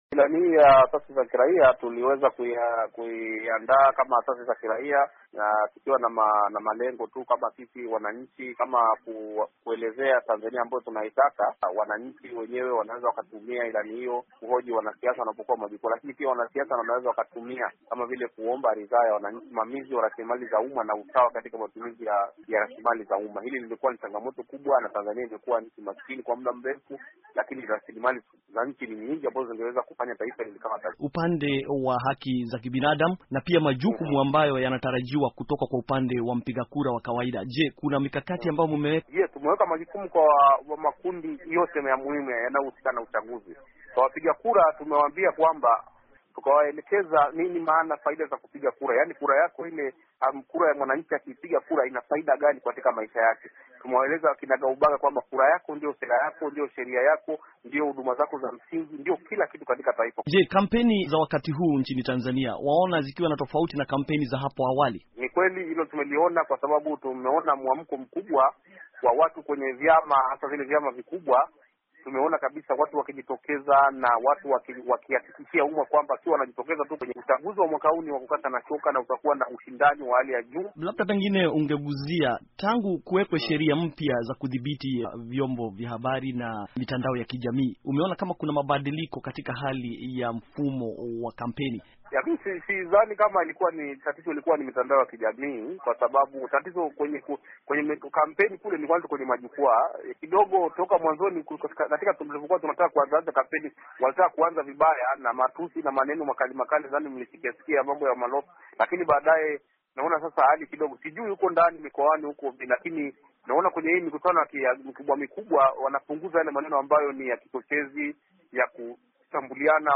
Mahojiano